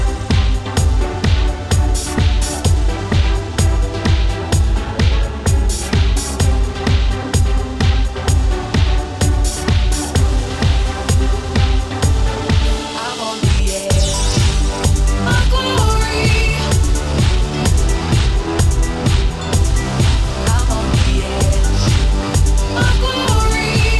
No Saxophone Solos Pop